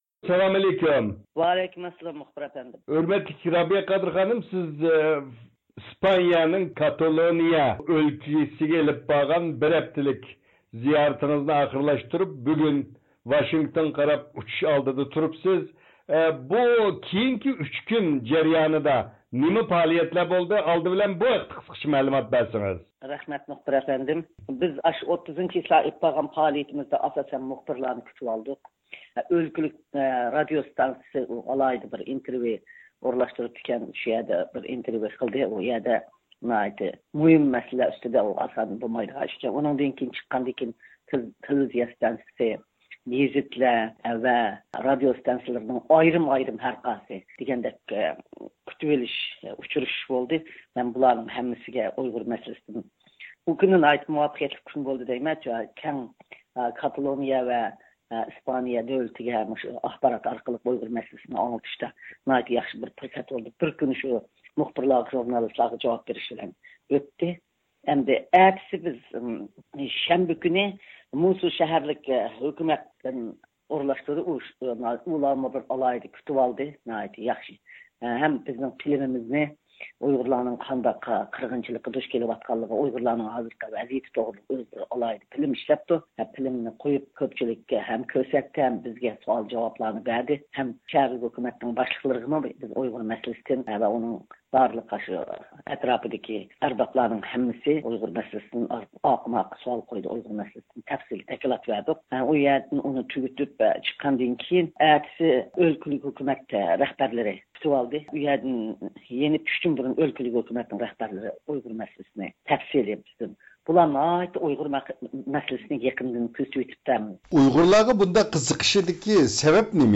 رابىيە قادىر خانىم ئىسپانىيەدىن ئامېرىكىغا قاراپ ئۇچۇش ئالدىدا تېلېفون زىيارىتىمىزنى قوبۇل قىلىپ، ئۆزىنىڭ كاتالونىيە زىيارىتىنىڭ كېيىنكى 3 كۈنلۈك پائالىيىتى توغرىسىدا مەلۇمات بەرگەندىن سىرت يەنە بۇ قېتىمقى ئىسپانىيە زىيارىتىگە باھا بېرىپ، گەرچە بۇنىڭ تۇنجى قېتىملىق زىيارەت بولسىمۇ، ئەمما ناھايىتى ئۇتۇقلۇق ئۆتكەنلىكىنى بايان قىلدى.